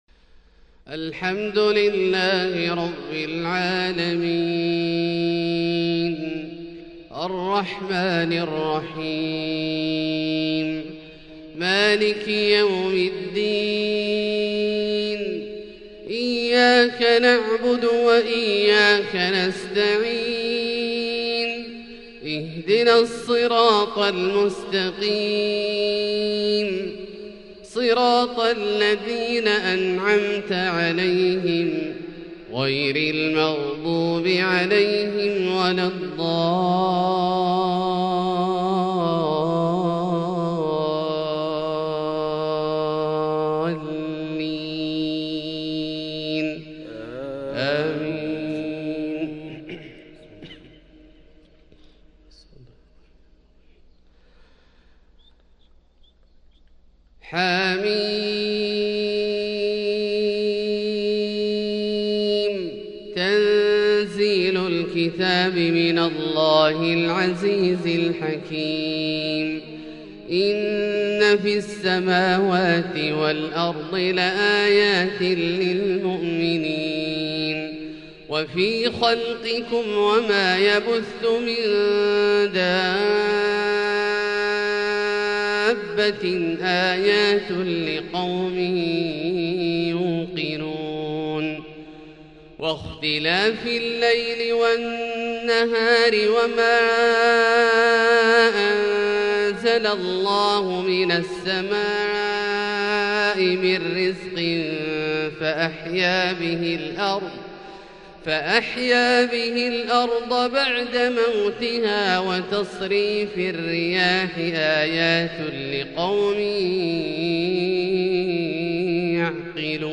فجر 7-7-1442هـ | من سورة الجاثية {1-26} > ١٤٤٢ هـ > الفروض - تلاوات عبدالله الجهني